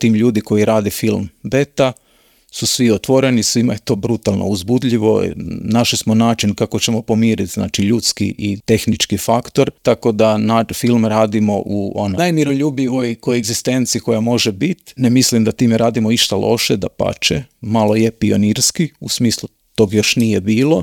Povodom najave filma, ugostili smo ga u Intervjuu Media servisa, te ga za početak pitali u kojoj je fazi film naziva BETA.